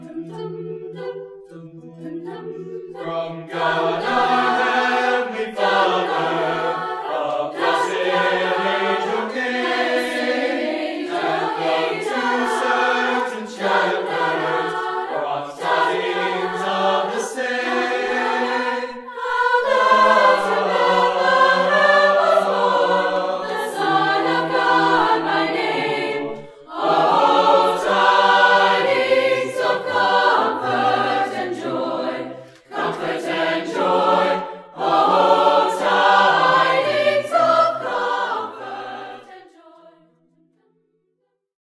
Swift Creations specializes in live and on location recordings in Pennsylvania.
Choir 2
choir2.mp3